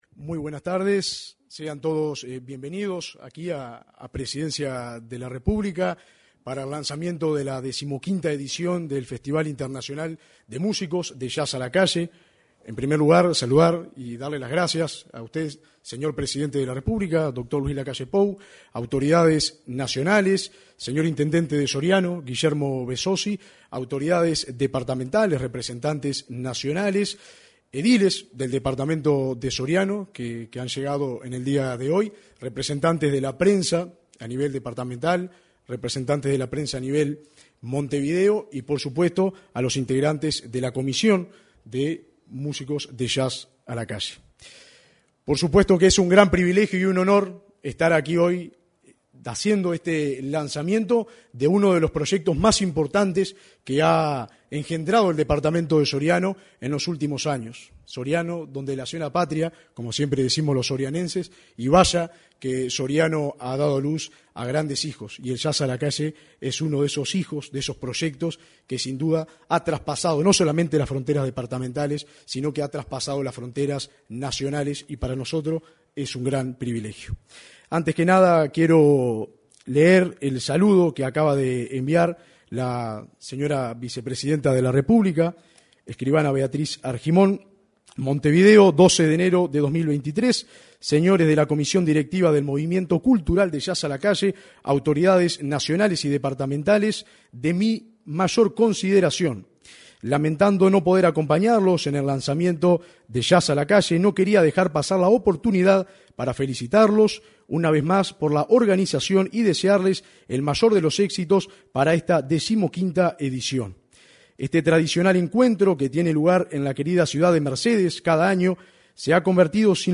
La ceremonia tuvo lugar en el salón de actos de la Torre Ejecutiva
participó el presidente de la República, Luis Lacalle Pou
el ministro de Turismo, Tabaré Viera